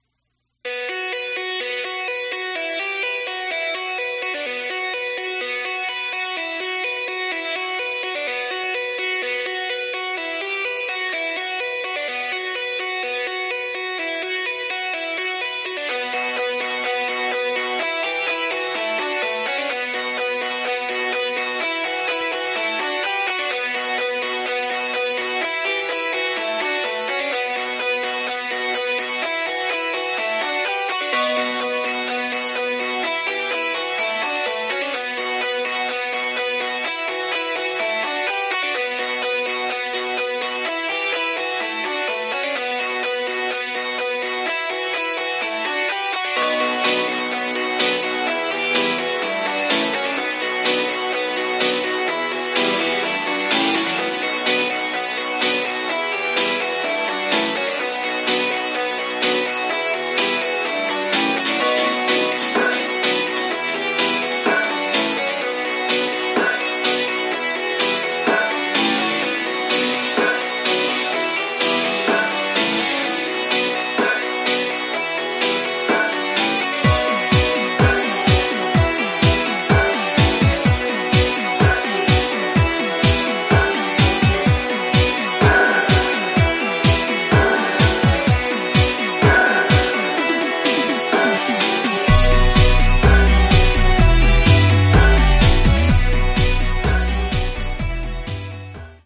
Genre: Dub Reggae.